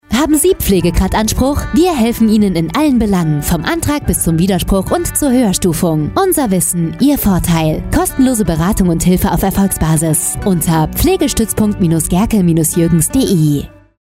Beratung Haushaltshilfe Unser Wissen - Ihr Vorteil Start Pflegestützpunkt Büro Gehrke & Jürgens Impressum Hier geht es weiter Leistungen der Pflegekassen Radio Spot
Funkspot_Pflegestuetzpunkt Buero.mp3